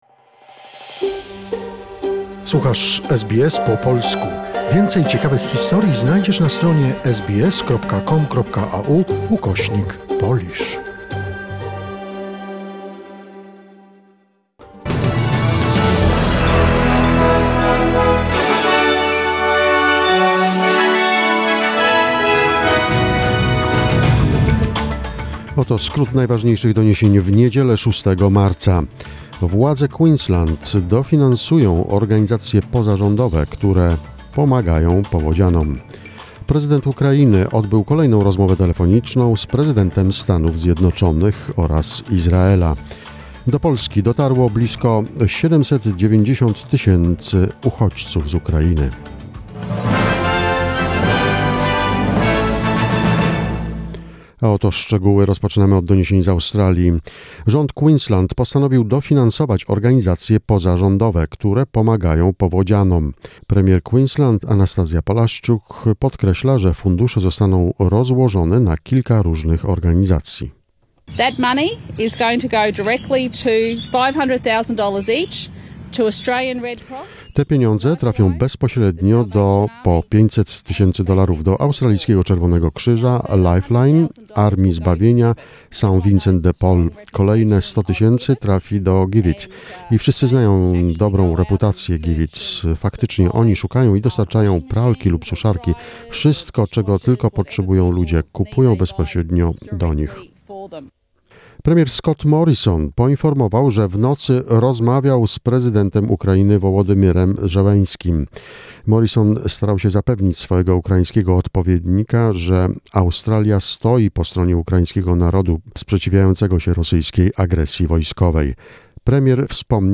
Wiadomości SBS, 6 marca 2022